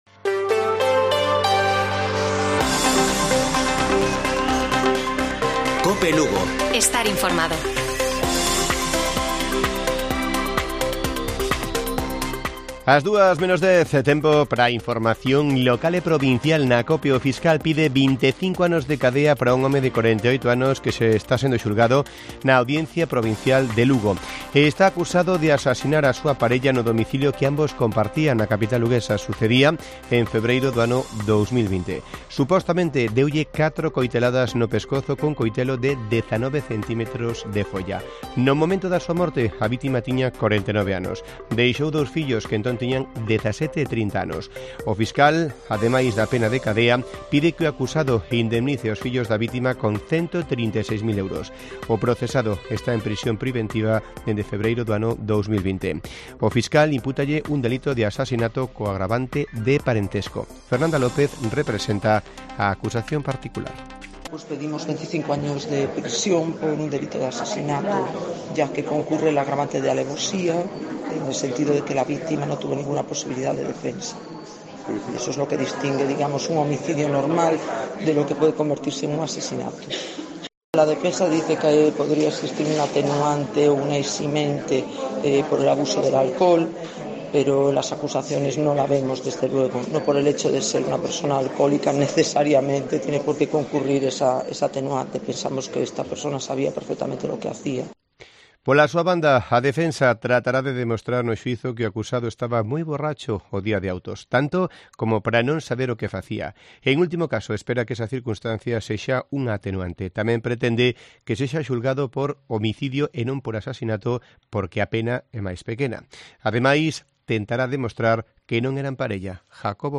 Informativo Mediodía de Cope Lugo. 04 DE JULIO. 13:50 horas